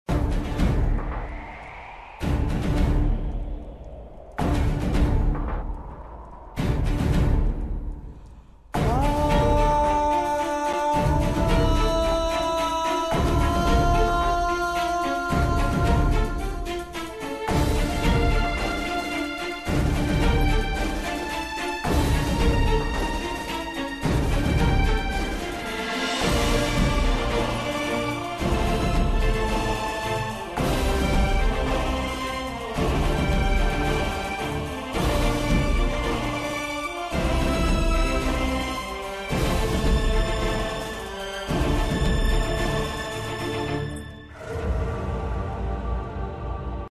Tv Serials Full Songs